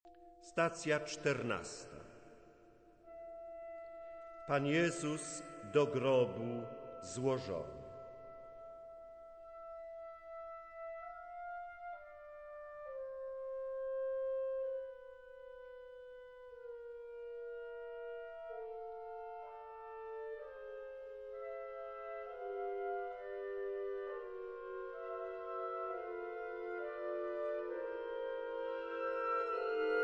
sopran/soprano
baryton/baritone